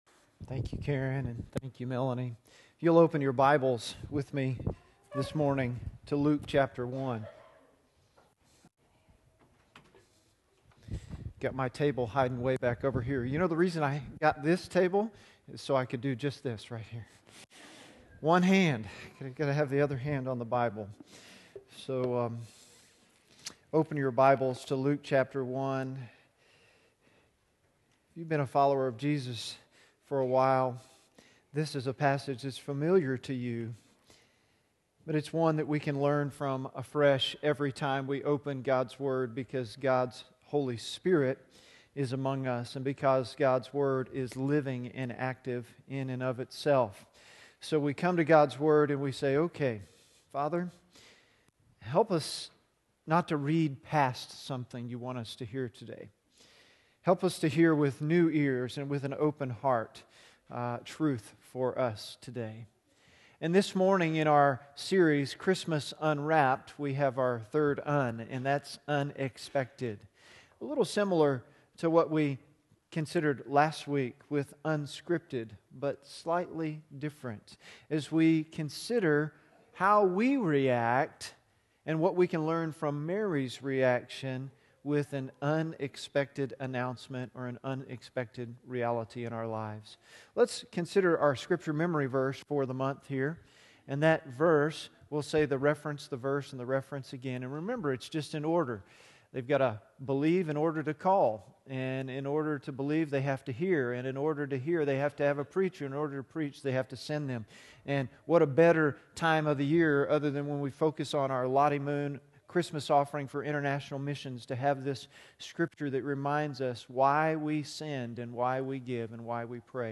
Luke 1:26-38 Sermon notes on YouVersion Christmas Unwrapped: Unexpected